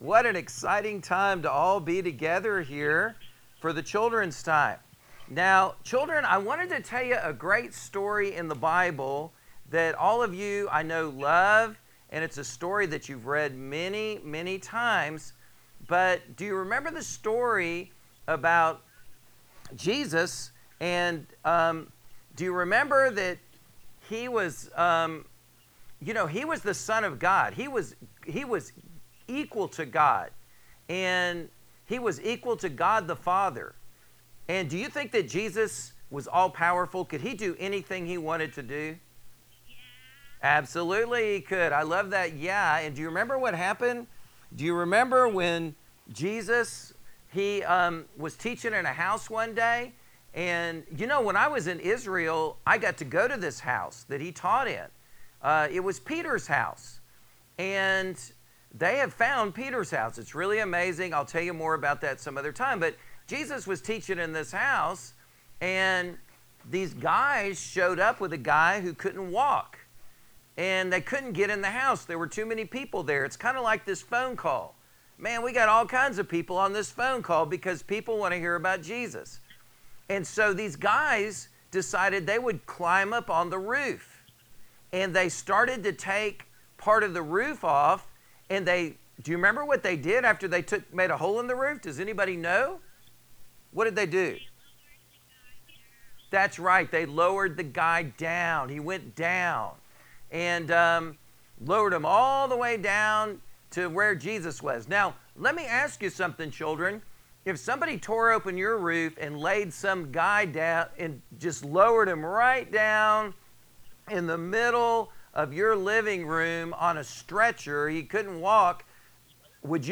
The Bible Virtual Study resources below from the Book of Revelation presented live on Sunday, March 29, 2020, at 6:00 PM.
A phone number and ID code were provided on the top of the BIBLE STUDY NOTES page for participants to call in and listen to the study live.